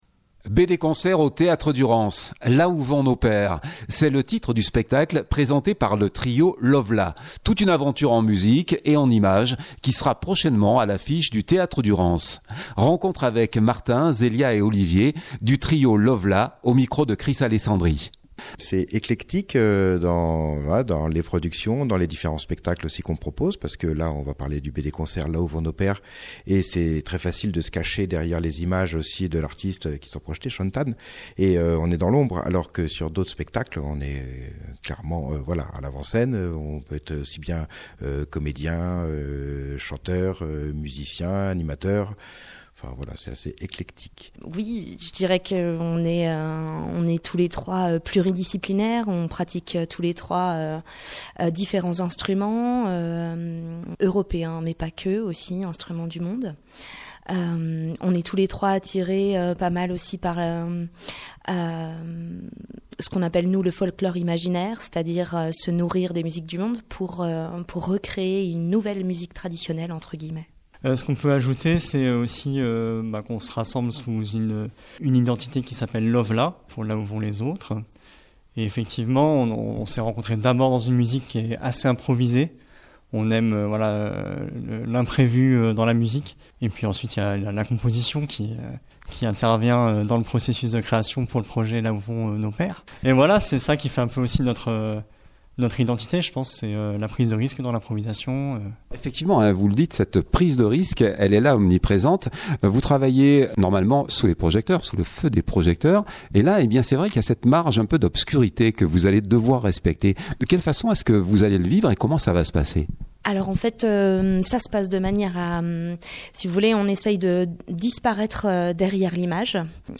répondent aux questions